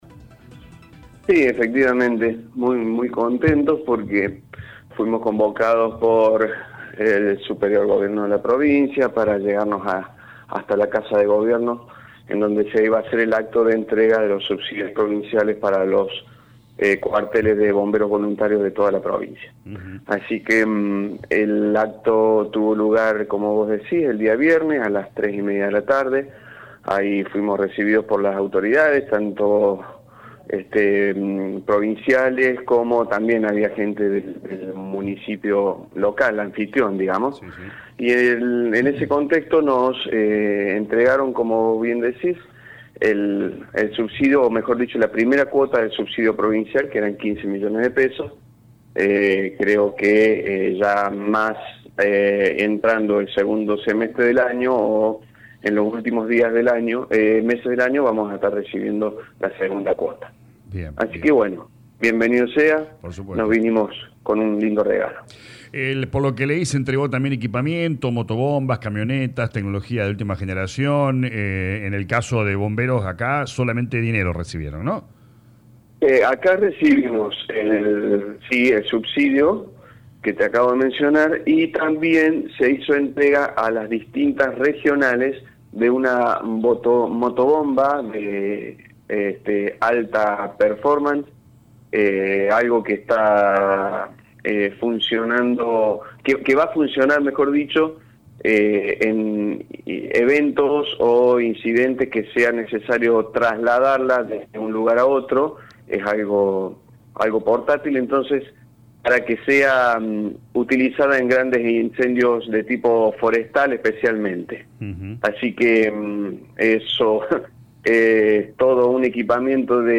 dialogó con LA RADIO 102.9 explicando que fueron convocados por el Gobierno.